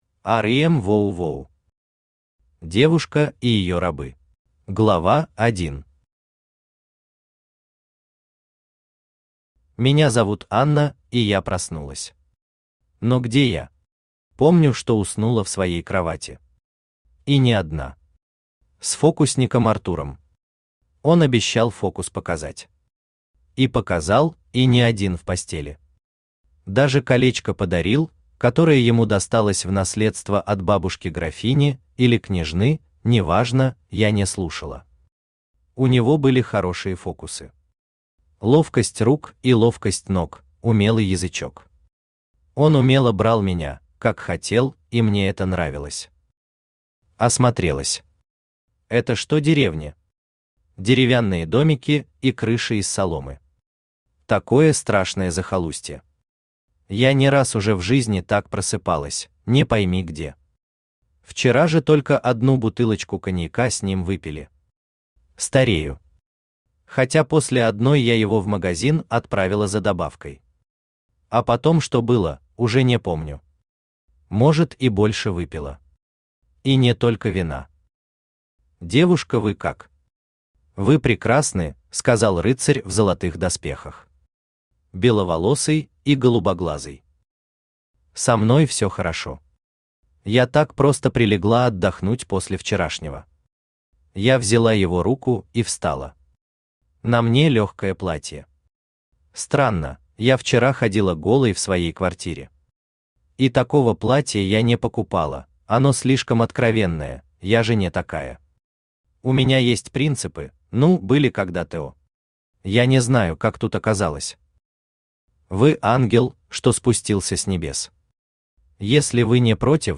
Аудиокнига Девушка и ее рабы | Библиотека аудиокниг
Читает аудиокнигу Авточтец ЛитРес